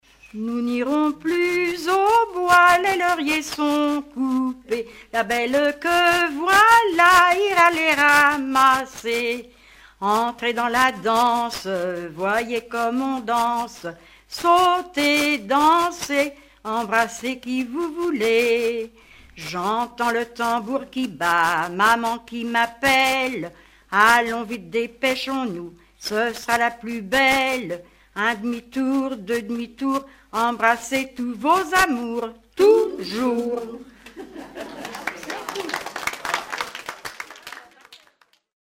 Xanton-Chassenon
Rondes enfantines à baisers ou mariages
danse : ronde à embrasser